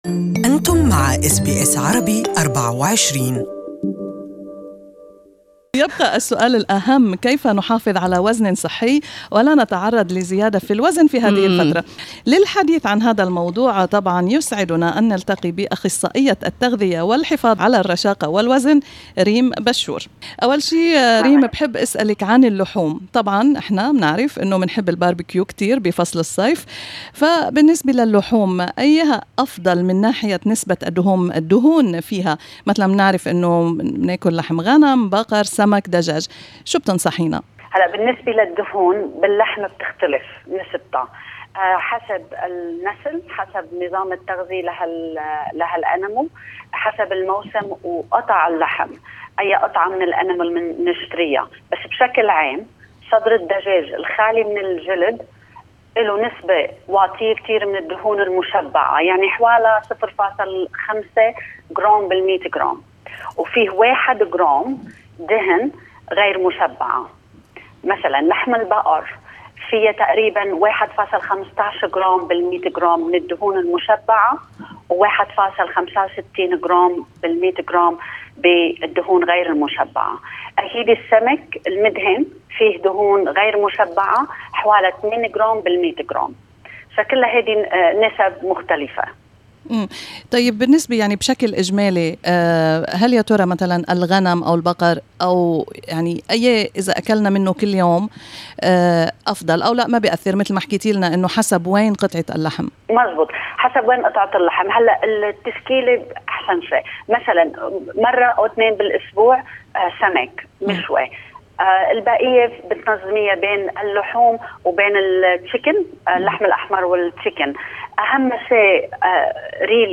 وتصدر الماء والحليب قائمة المشروبات الأقل تأثيرا على زيادة الوزن. استمعوا إلى اللقاء كاملا تحت الشريط الصوتي.